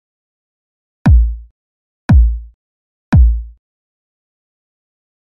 جلوه های صوتی
دانلود صدای ضربه باس 1 از ساعد نیوز با لینک مستقیم و کیفیت بالا